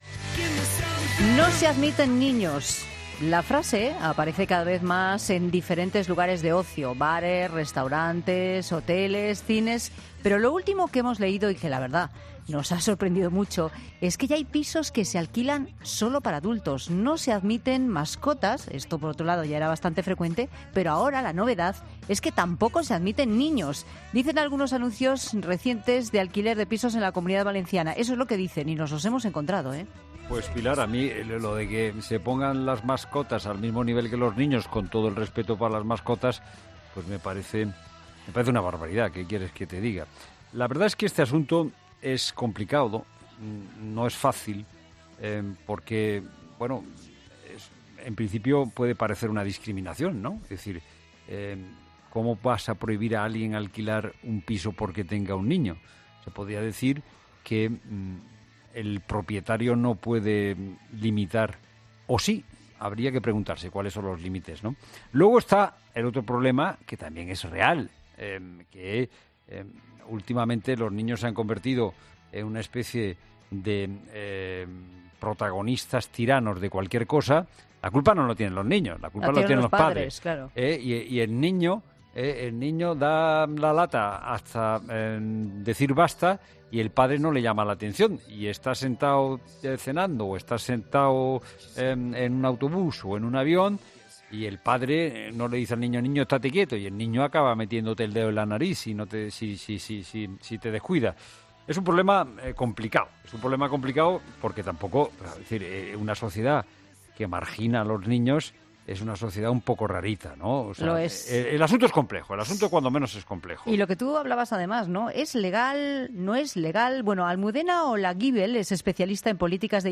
ESCUCHA LA ENTREVISTA COMPLETA EN 'LA TARDE' 'Daniel el Travieso', 'Este chico es un demonio'... las trastadas de los niños parece que se llevan mejor en el cine, pero ¿y en la realidad?